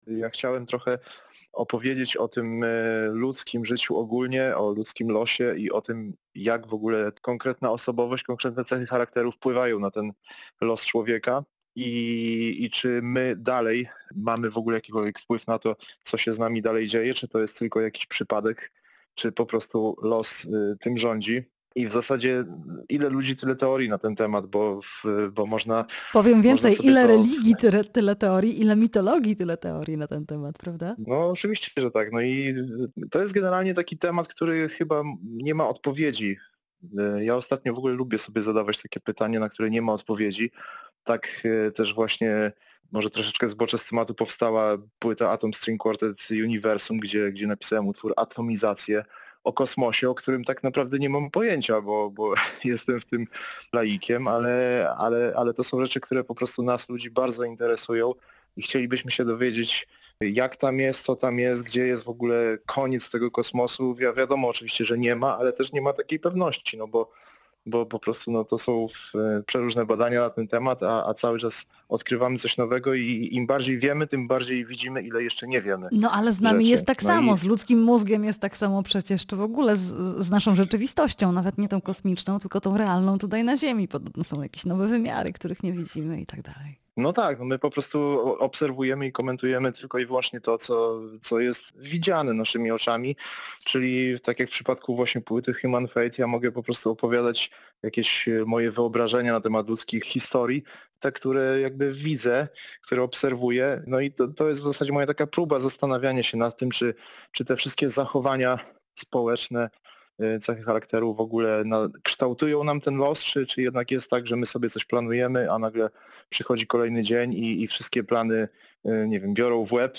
Jazz a vu: Rozmowa